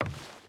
Footsteps / Wood / Wood Walk 5.wav
Wood Walk 5.wav